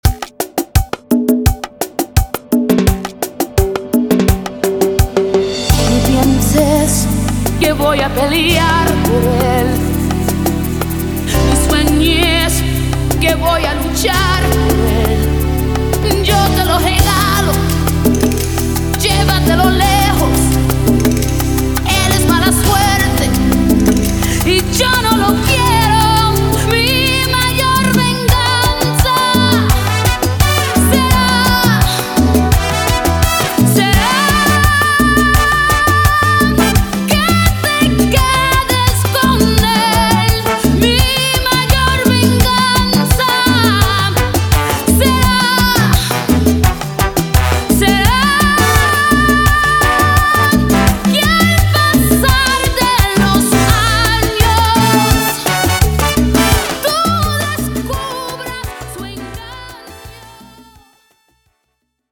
salsa remix